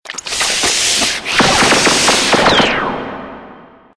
File:Sfx animation voltelezoid win.ogg